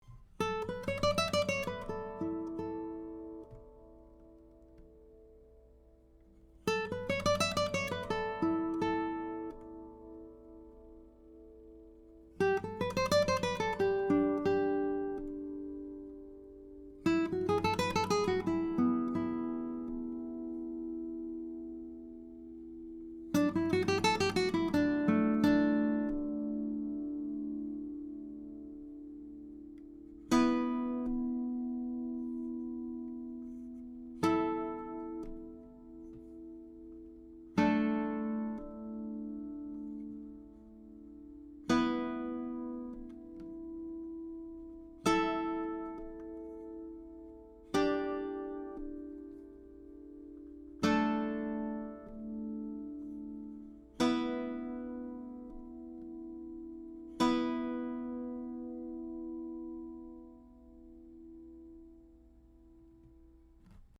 Recorded with a couple of prototype TAB Funkenwerk / German Masterworks CG•OA-1 condenser mics into a Trident 88 recording console using Metric Halo ULN8 converters:
1 | Resonance Test
(Showing the guitar's excellent Sympathetic Resonance)
CGoa1sT88Resonance.mp3